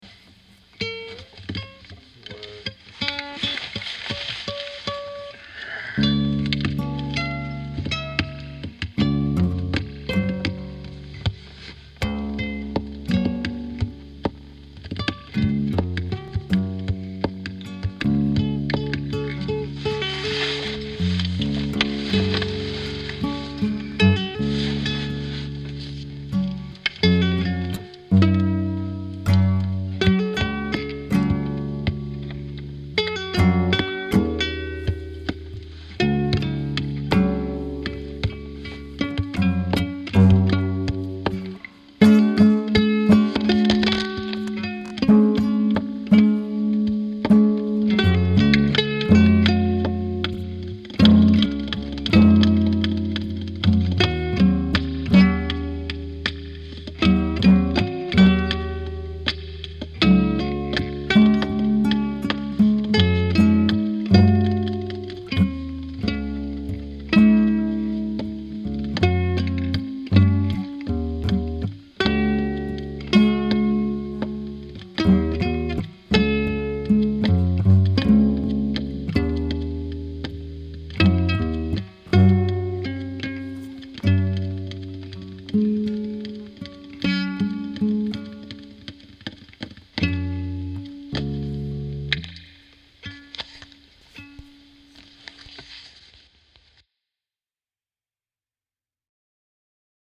This is 3 tracks recorded with the iPhone app 4-Track. Single take, no editing. Sorry about the blown out bass on the first track, and of course the slop.